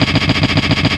《マシンガンの音》フリー効果音
ドドドドド、またはガガガガガ、というマシンガンのような効果音。
machine-gun-sound.mp3